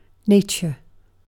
nature-uk.mp3